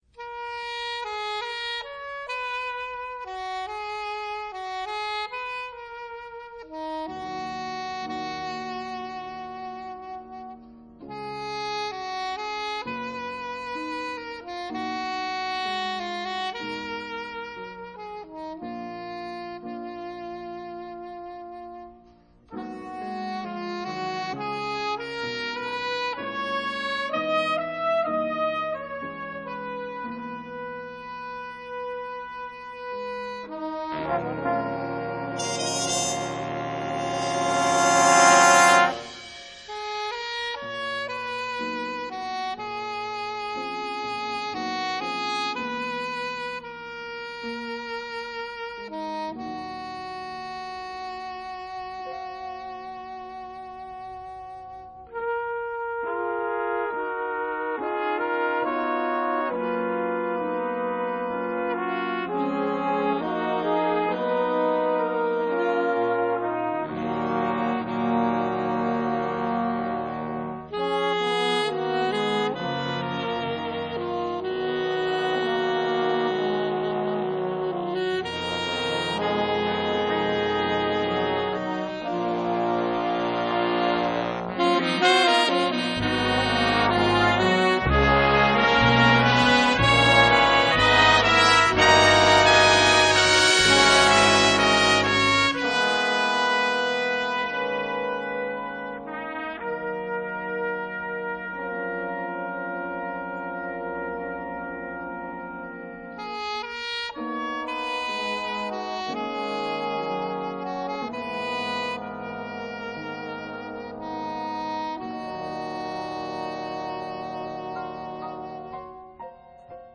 Big band jazz